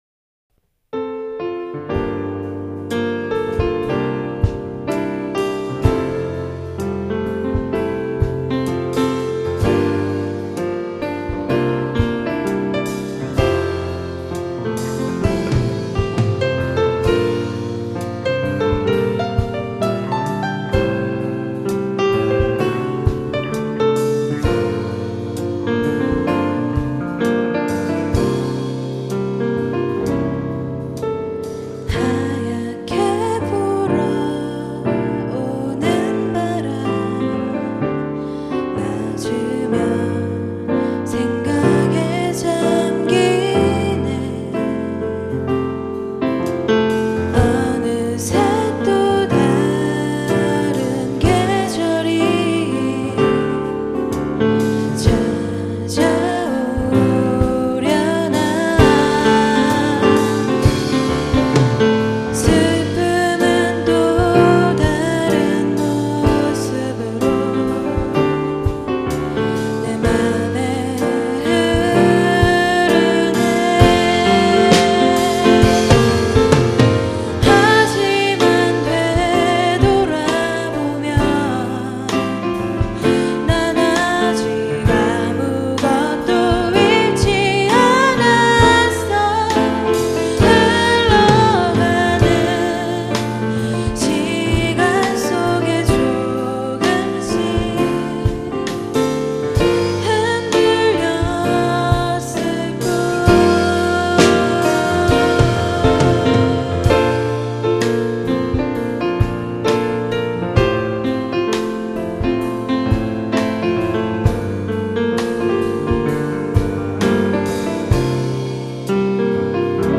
2004년 제 24회 정기 대공연
홍익대학교 신축강당
노래
베이스
드럼
신디사이저